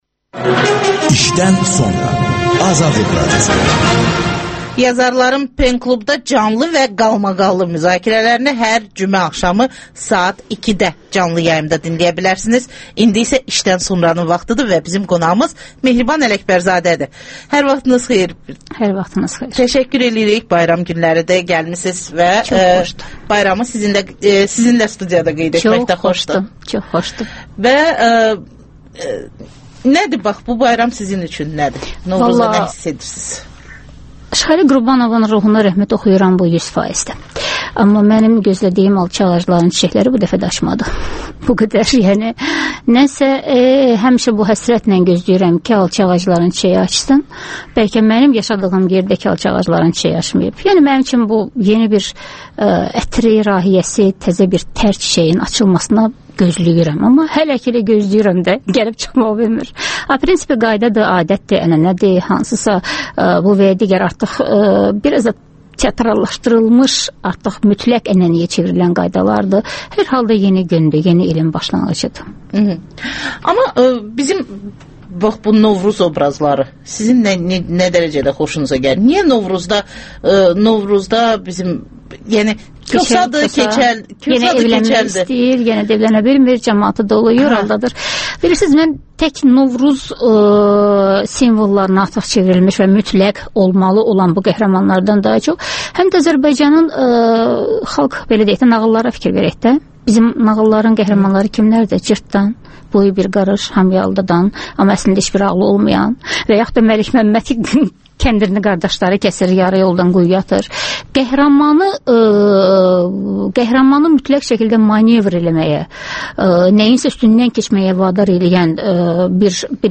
açıq söhbət